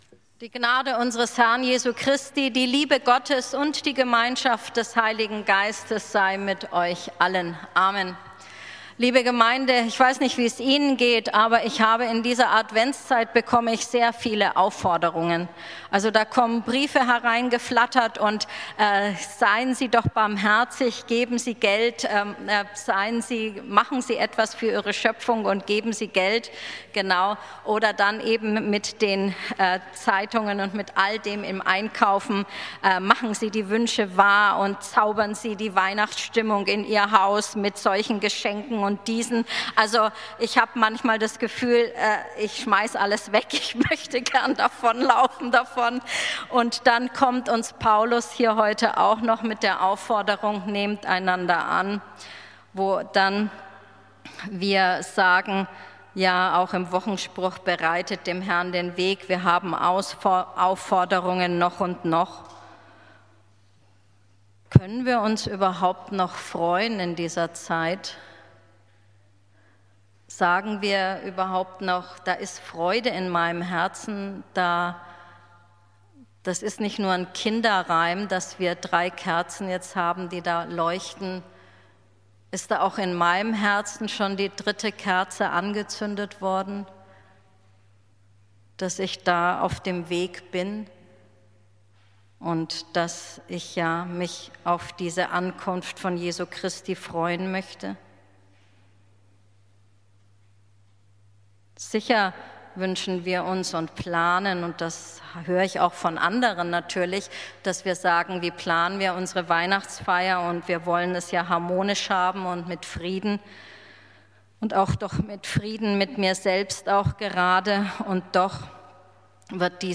Predigt vom 08.12.2024 Spätgottesdienst